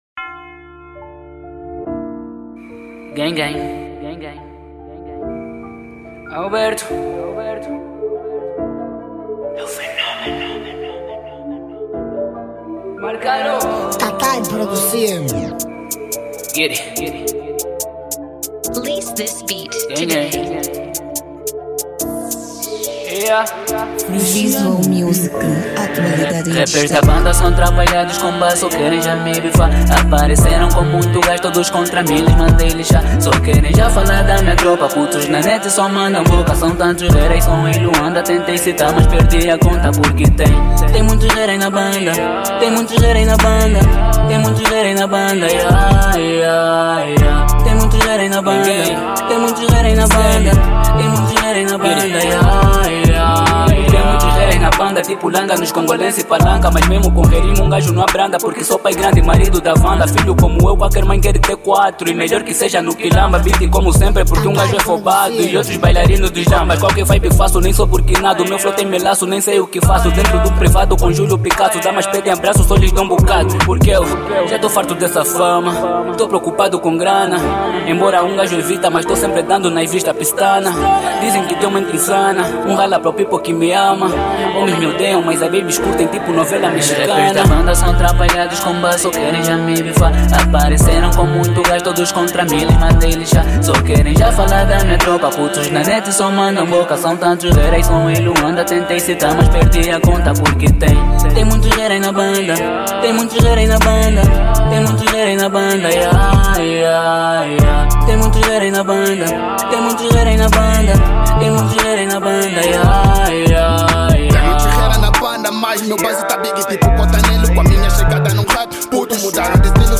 Género: Zouk